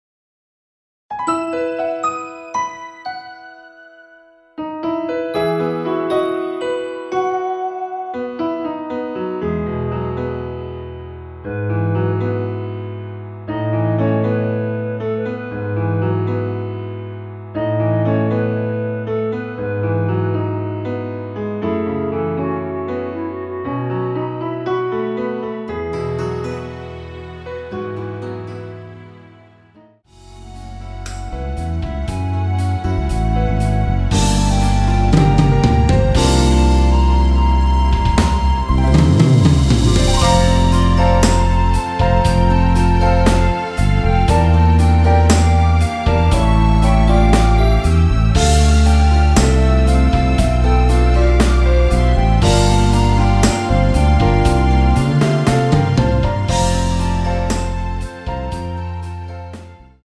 *부담없이즐기는 심플한 MR~
원키 멜로디 포함된 MR입니다.
앞부분30초, 뒷부분30초씩 편집해서 올려 드리고 있습니다.
중간에 음이 끈어지고 다시 나오는 이유는